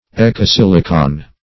Ekasilicon \Ek`a*sil"i*con\, n. [Skr.